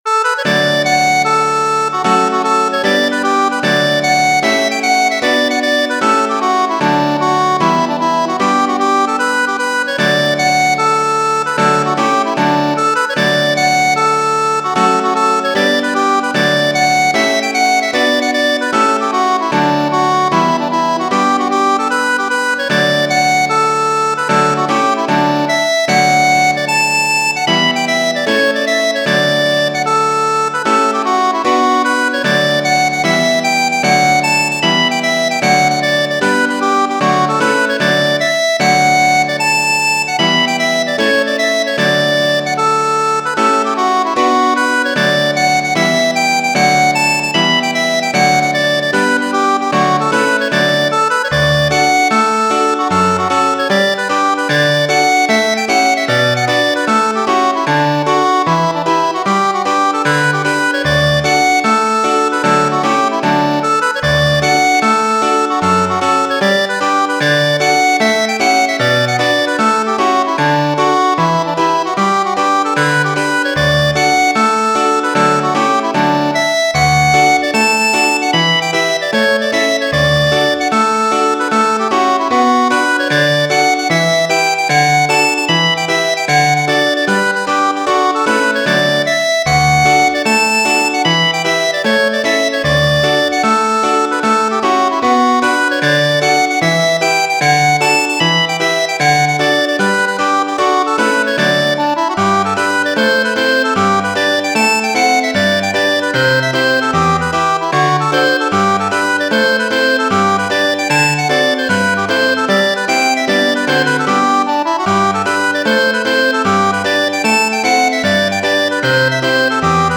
h'pipes